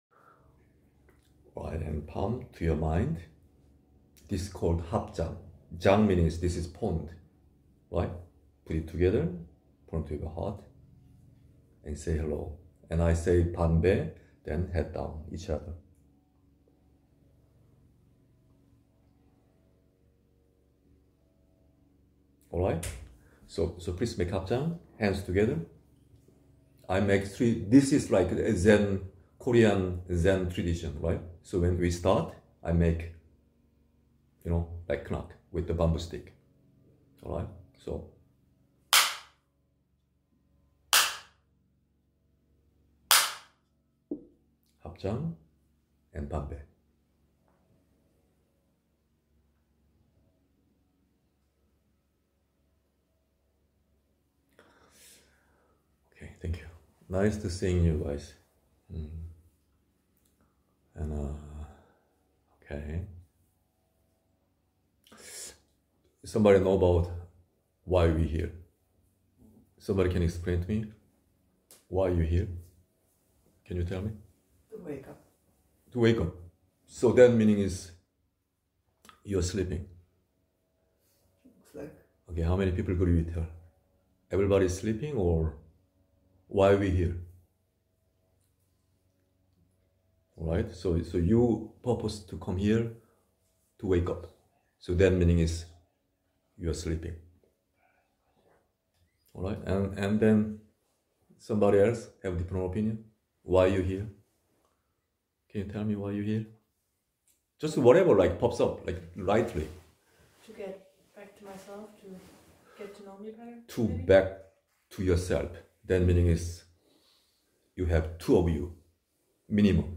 If you are new here, please watch this introductory video from a recent retreat.